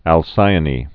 (ăl-sīə-nē)